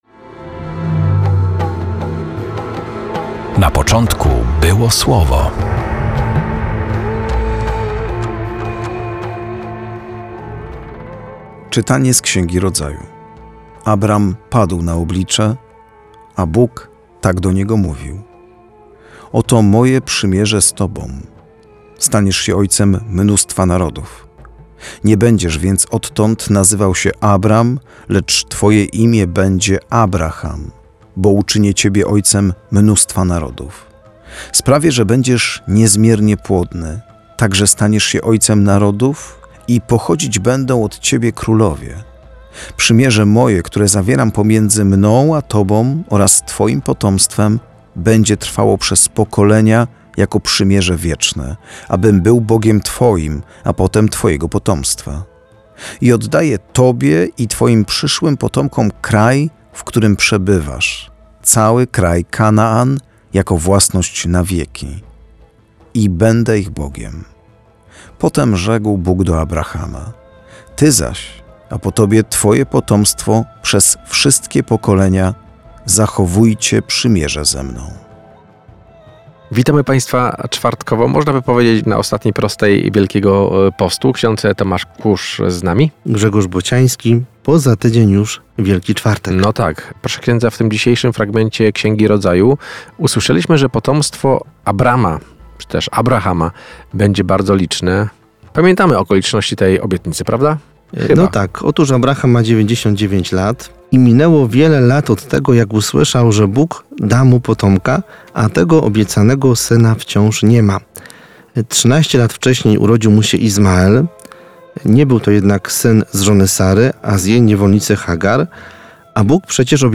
Perły ukryte w liturgii słowa odkrywają księża